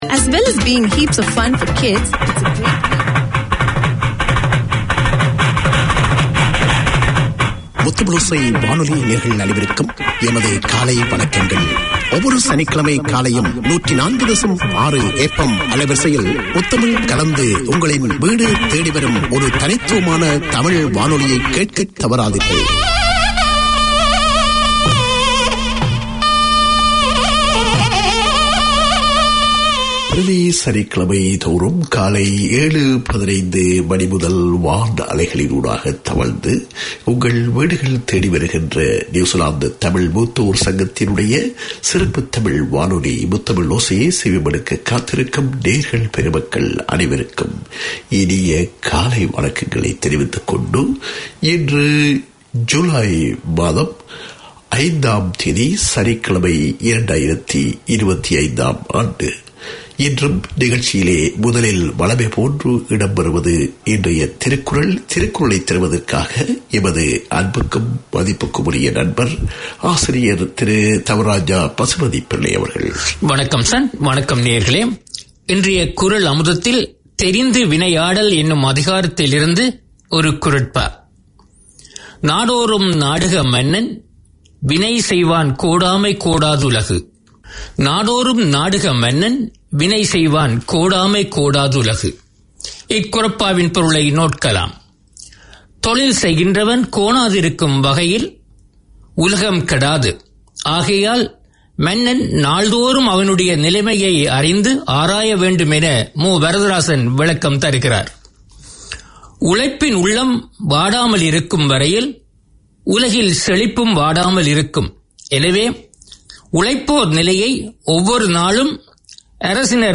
Tamil seniors are up early every Saturday to hear their half hour of radio. On air is a wealth of interviews, music, news, community news, health information, drama and literature.